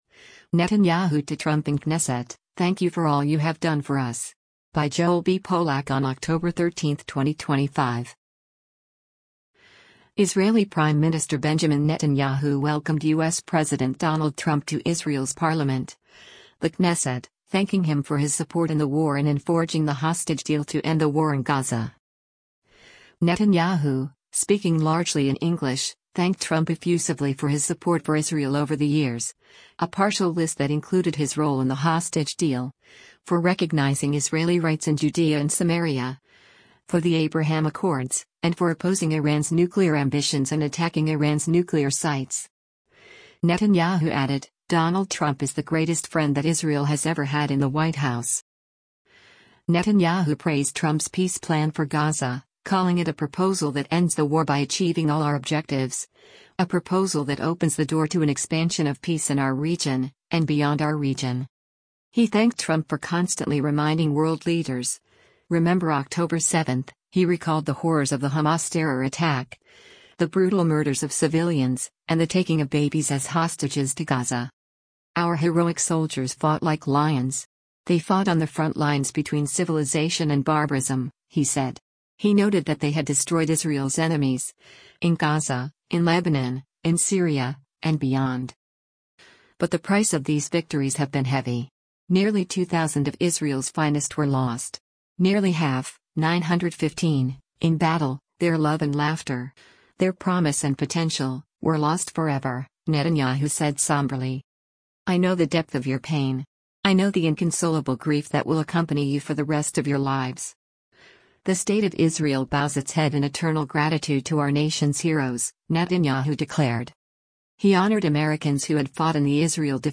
Israeli Prime Minister Benjamin Netanyahu addresses the Knesset as President Donald Trump